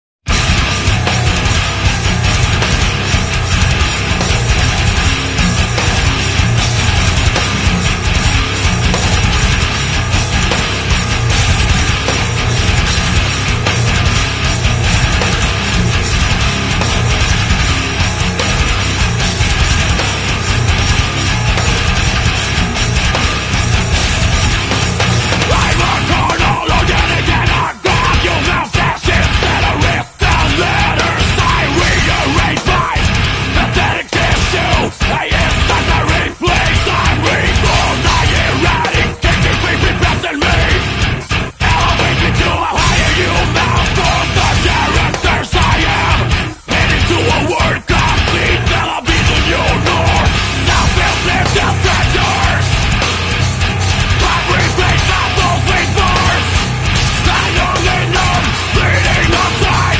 metal extremo